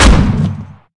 grenadelaunch.ogg